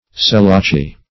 Selachii \Se*la"chi*i\, n. pl.